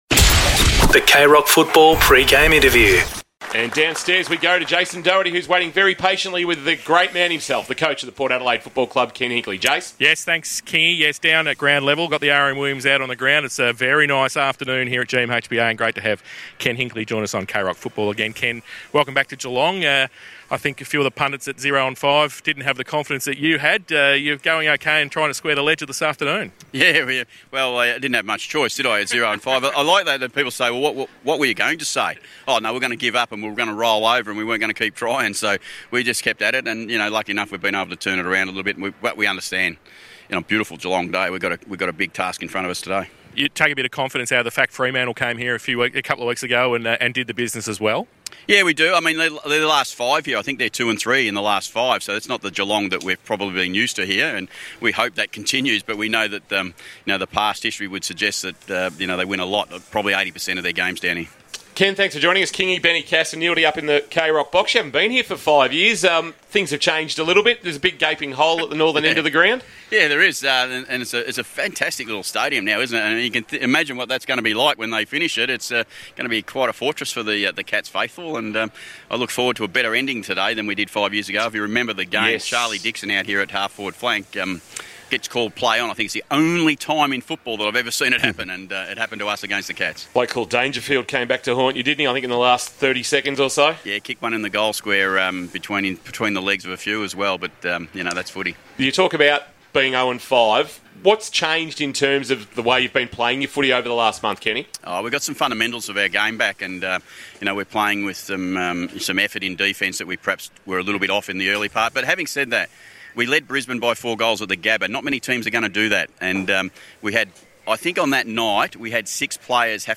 2022 - AFL ROUND 10 - GEELONG vs. PORT ADELAIDE: Pre-match Interview - Ken Hinkley (Port Adelaide Coach)